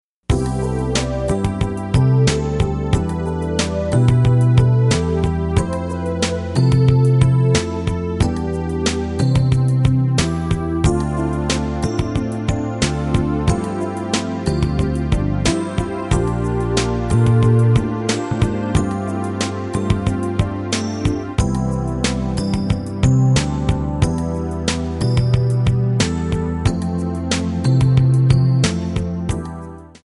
F#
Backing track Karaoke
Pop, 1990s